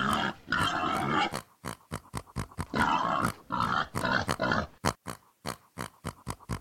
beyond/Assets/Sounds/Enemys/Boar/Boar.ogg at main
Boar.ogg